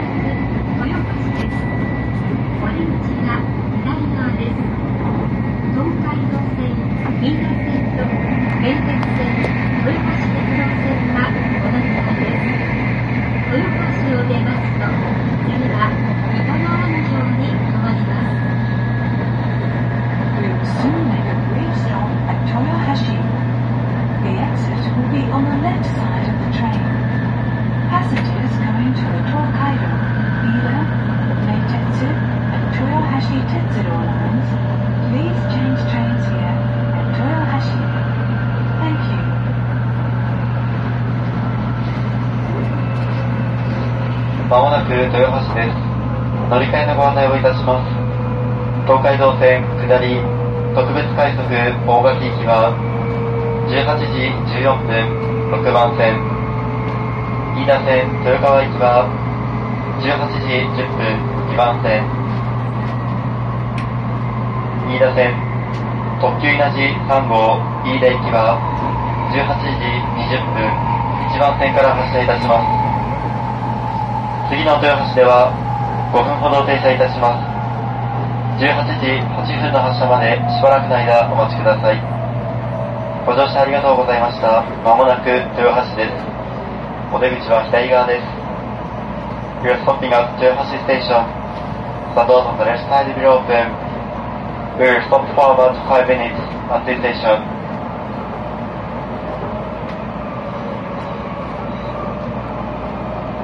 [走行音] ‘220504 こだま739号新大阪行き 豊橋到着前アナウンス